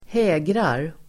Ladda ner uttalet
Uttal: [²h'ä:grar]